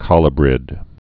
(kŏlə-brĭd, kŏlyə-)